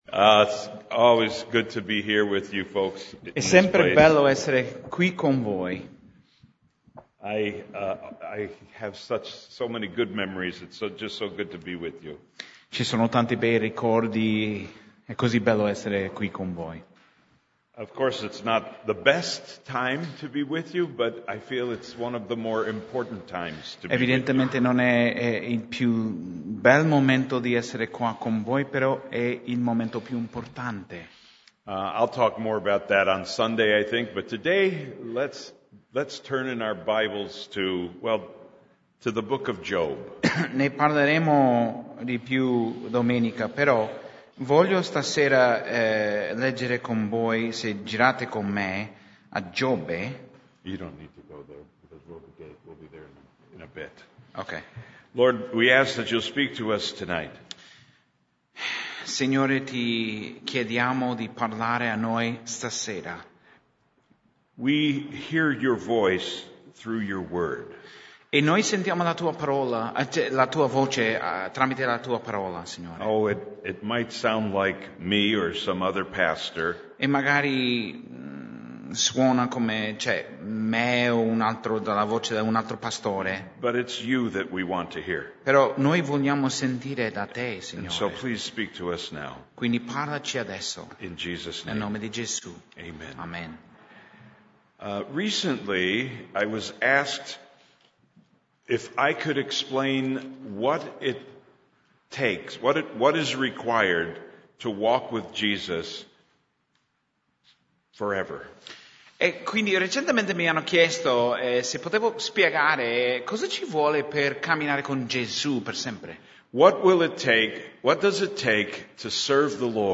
Studio biblico di Mercoledì 14 Giugno